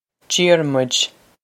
Diarmuid Jeer-mwij
Pronunciation for how to say
This is an approximate phonetic pronunciation of the phrase.